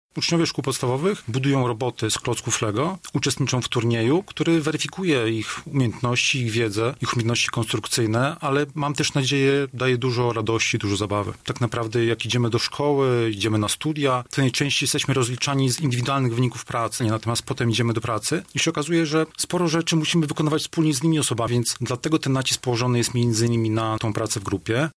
jeden z organizatorów